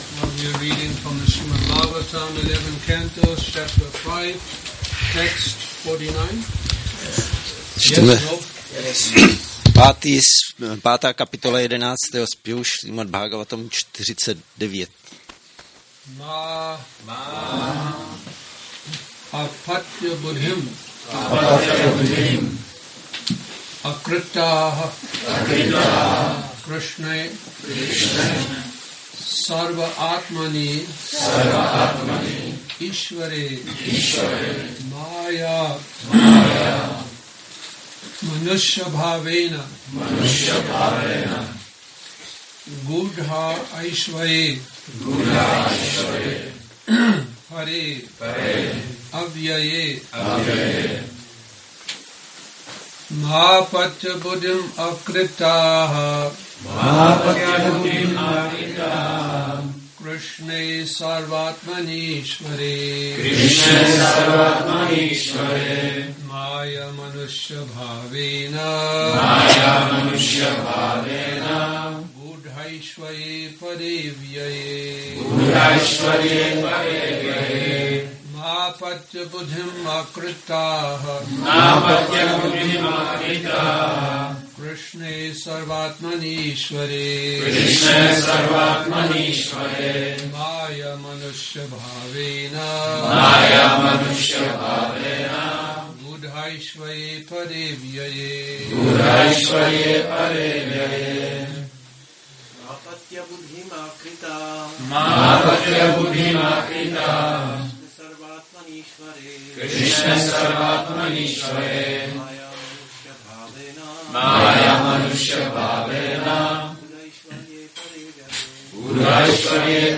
Přednáška SB-11.5.39 – Šrí Šrí Nitái Navadvípačandra mandir